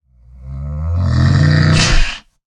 latest / assets / minecraft / sounds / mob / camel / stand3.ogg